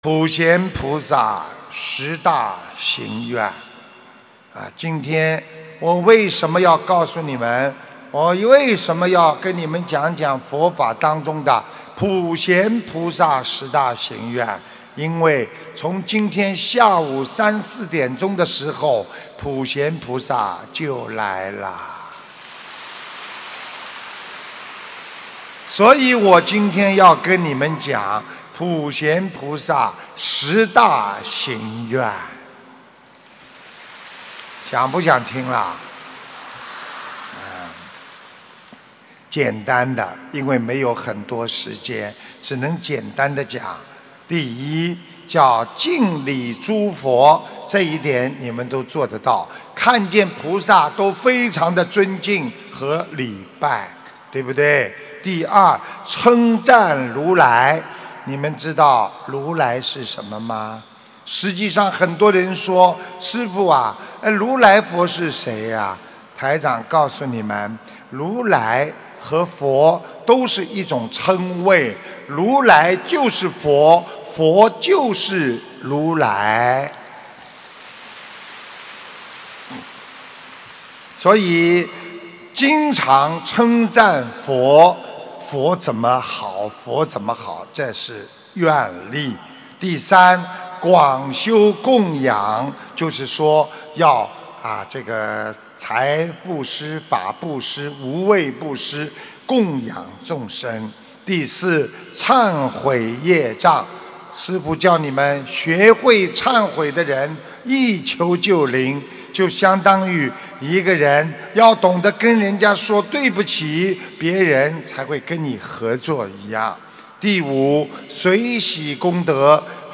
马来西亚·吉隆坡 170829 普贤菩萨十大行愿